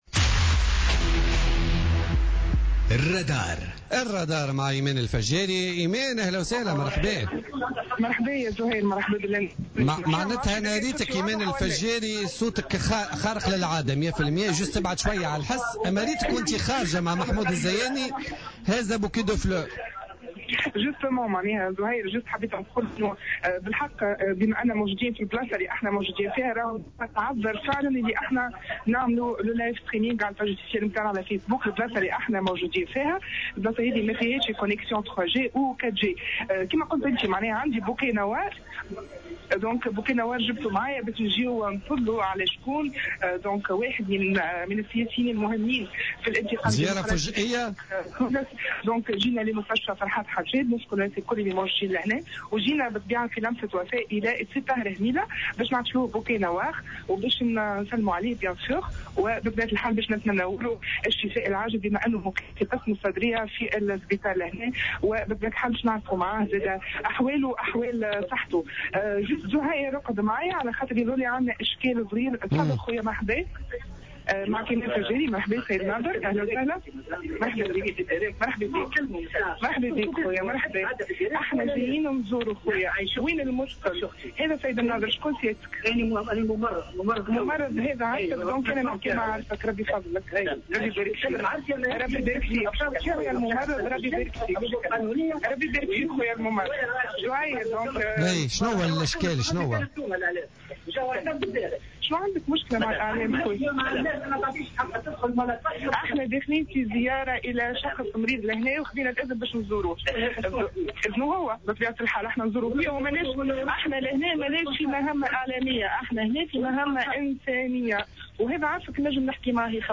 تحوّل فريق "الرادار" اليوم الاثنين 15 ماي 2017، إلى مستشفى فرحات حشاد لعيادة النائب السابق بالمجلس الوطني التأسيسي الطاهر هميلة الذي يمكث في المستشفى منذ حوالي 20 يوما، والاطمئنان على صحته.